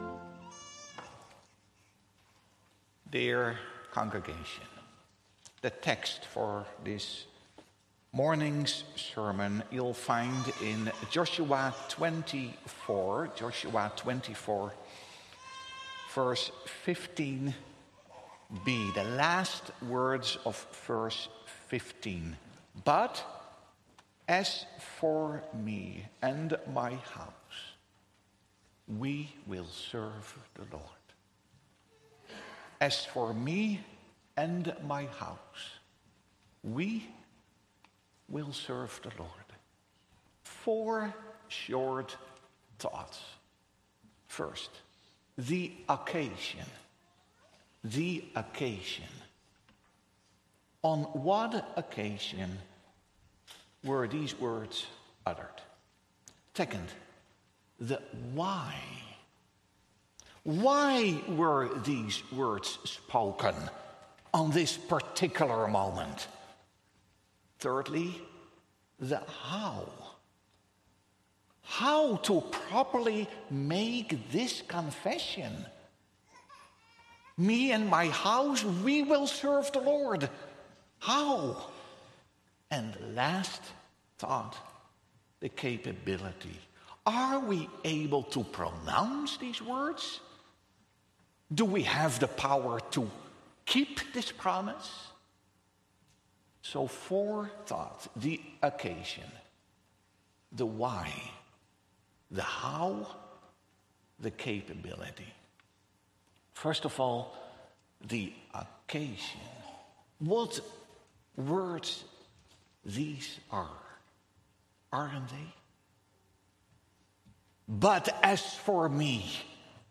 Sermon Audio | Providence Reformed Church